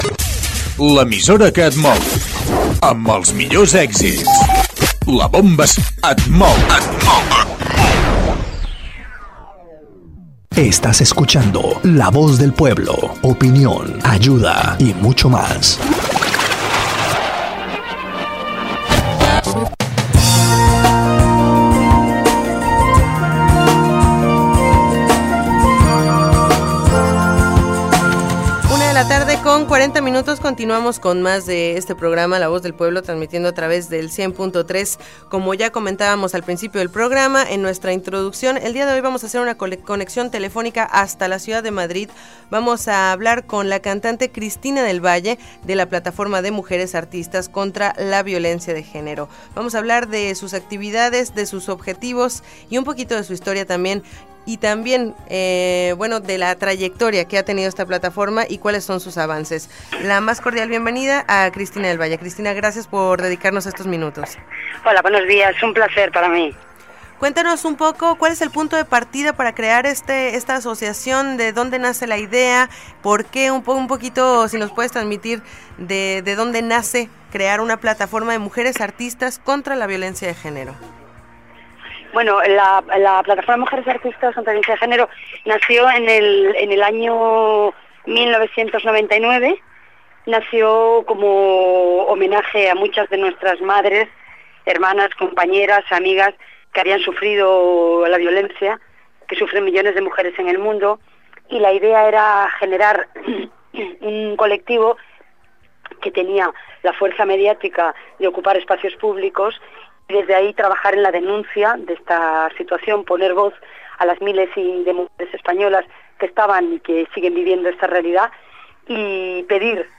Identificació de l'emissora i del programa i entrevista telefònica a la cantant Cristina del Valle sobre la violència de gènere
Informatiu